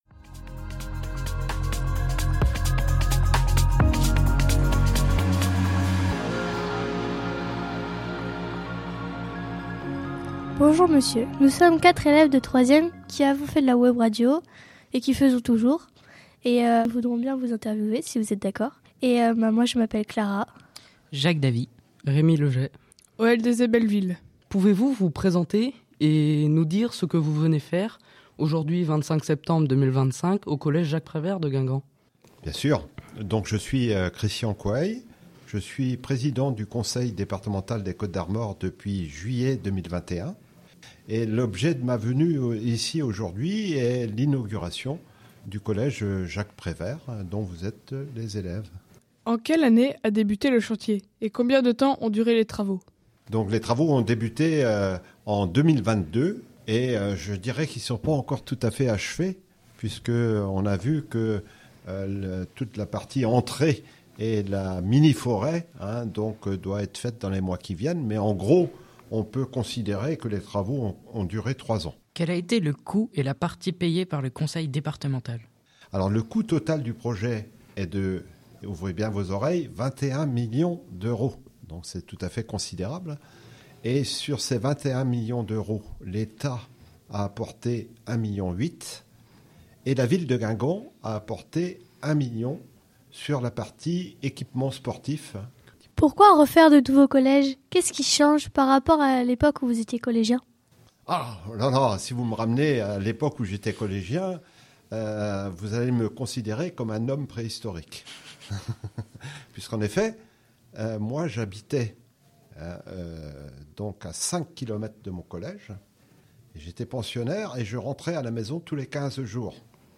Format: MP3 Interview 9.1 Mio Inauguration de notre nouveau collège